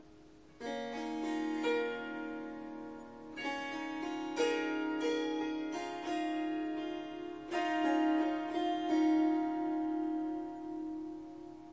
Swarmandal印度竖琴曲谱 " 竖琴弹奏曲谱6
这个奇妙的乐器是Swarmandal和Tampura的结合。
它被调到C sharp，但我已经将第四个音符（F sharp）从音阶中删除了。
这些片段取自三天不同的录音，因此您可能会发现音量和背景噪音略有差异。一些录音有一些环境噪音（鸟鸣，风铃）。
Tag: 竖琴 弦乐 旋律 Swarsangam 民族 Swarsangam 印度 即兴重复段 Surmandal Swarmandal 旋律